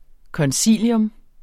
Udtale [ kʌnˈsiˀljɔm ]